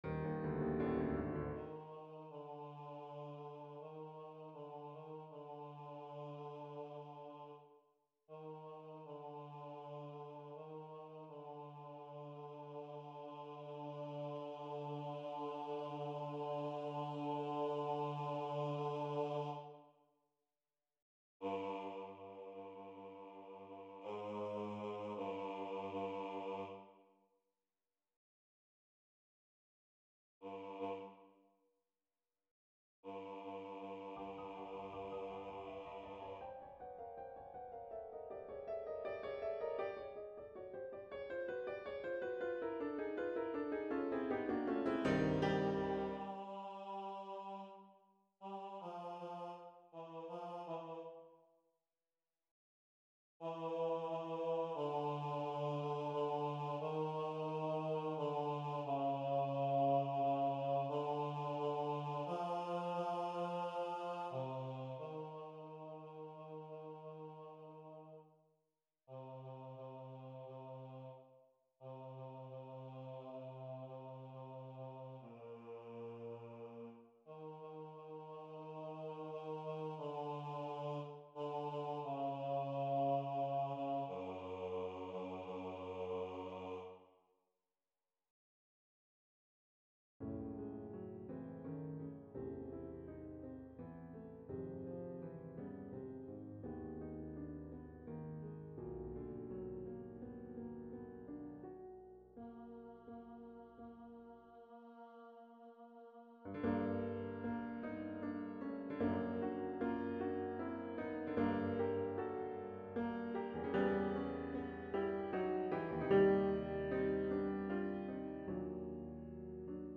Rehearsal Files
vab-bass-voice-only-1-feb-9-18.mp3